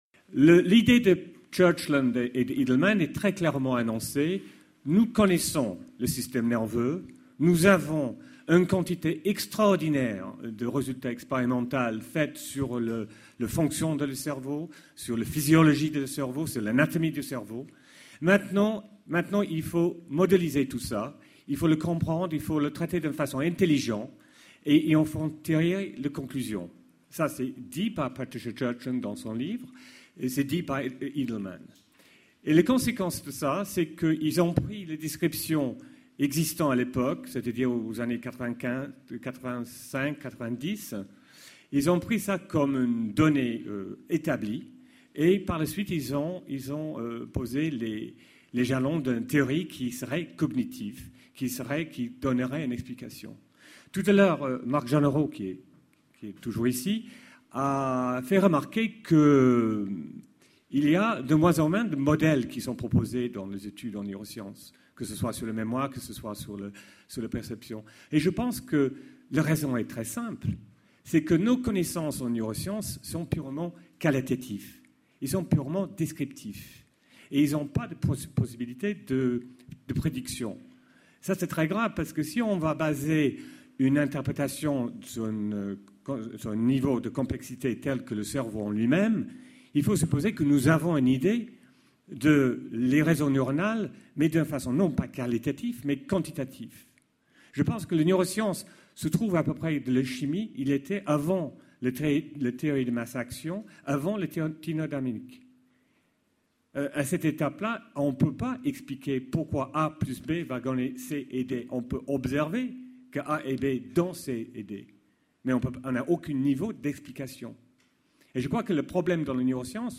Colloque La représentation du vivant : du cerveau au comportement